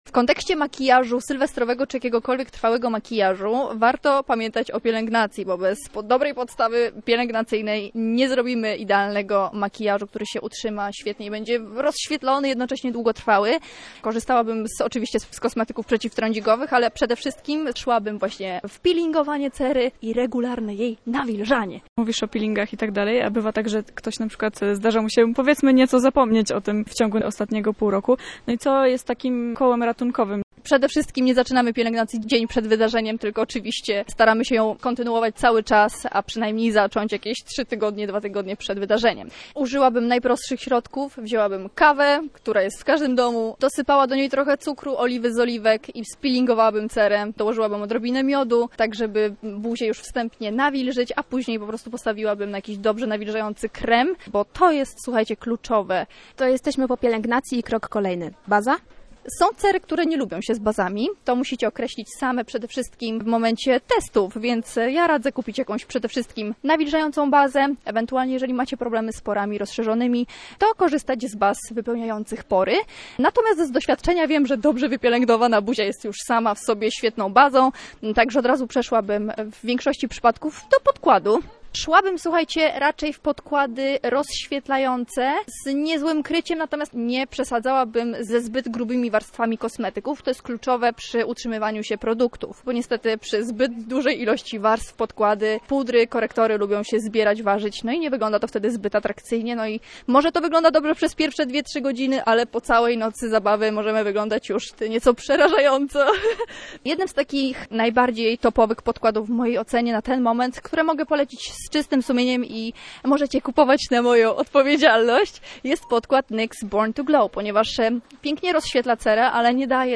Ale tym razem postanowiły porozmawiać z osobą, która testowaniem zajmuje się zawodowo.